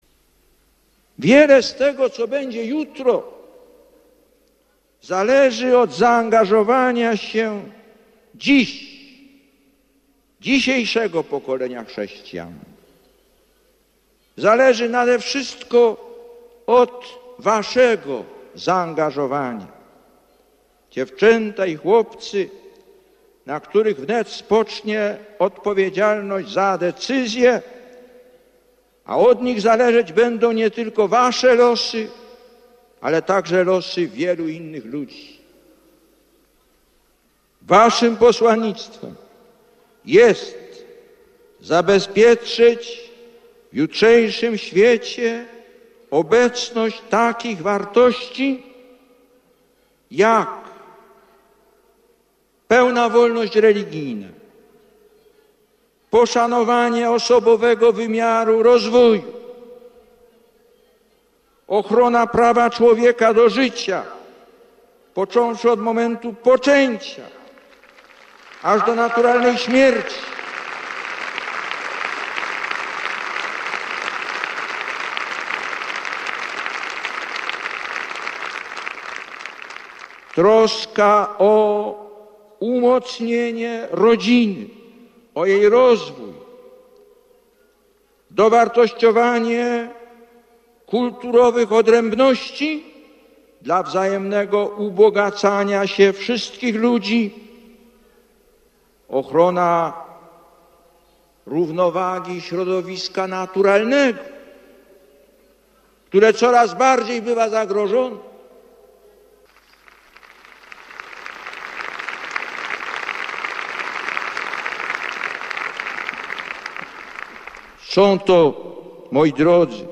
Mówi do nas Św. Jan Paweł II